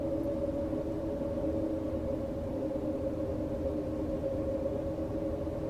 环境音 / 白噪音
白噪声房间里1.ogg